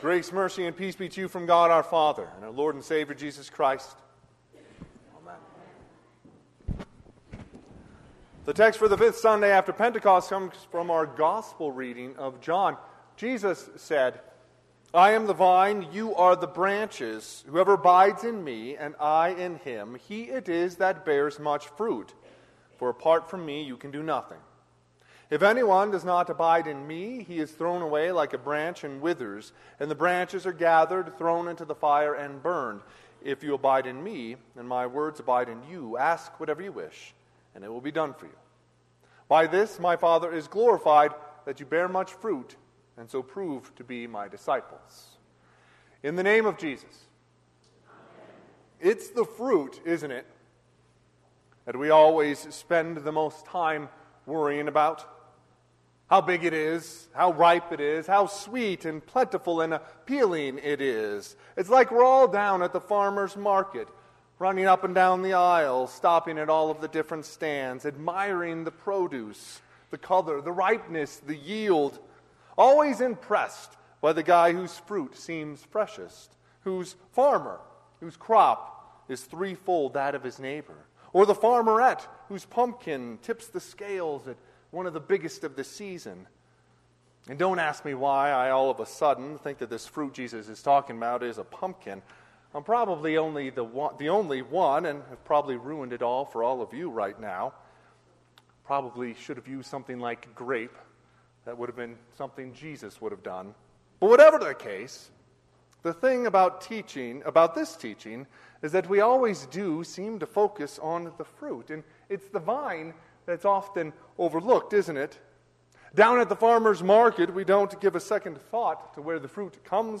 Sermon - 4/28/2024 - Wheat Ridge Lutheran Church, Wheat Ridge, Colorado
Fifth Sunday of Easter